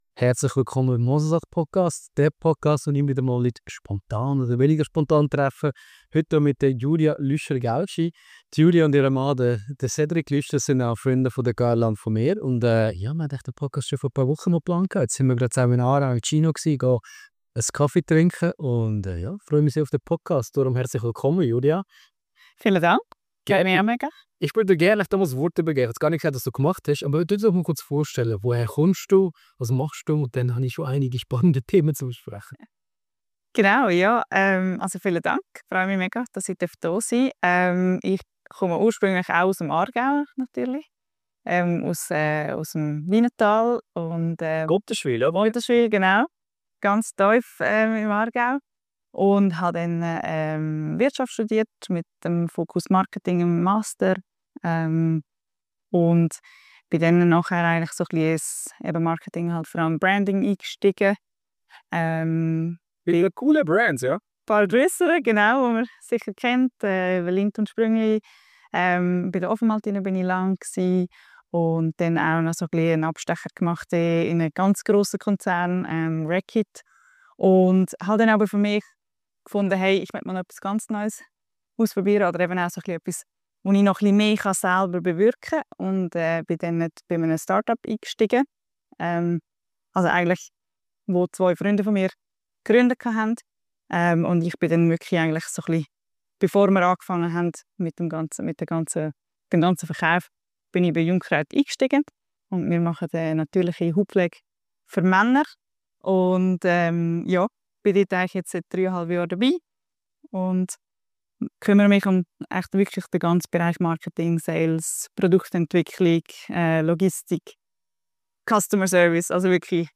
Ein persönliches Gespräch mit einer Macherin, die klare Werte vertritt und viel Energie mitbringt.